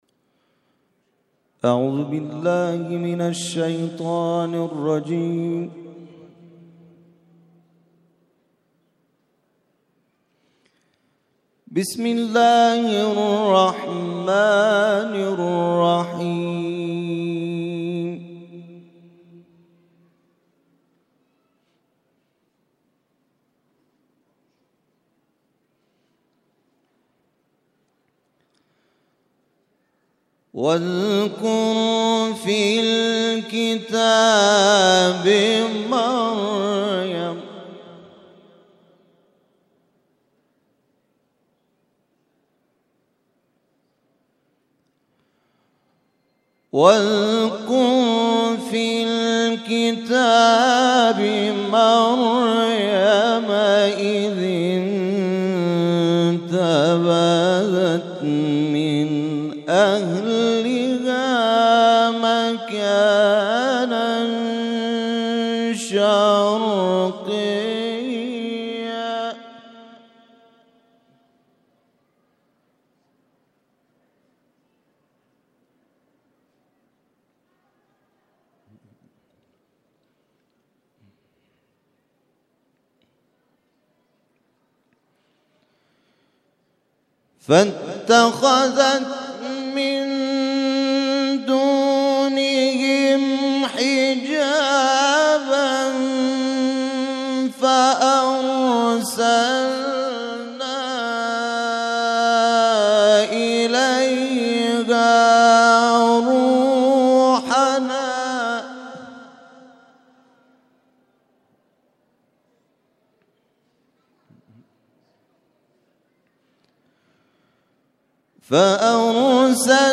تلاوت مغرب روز جمعه
تلاوت قرآن کریم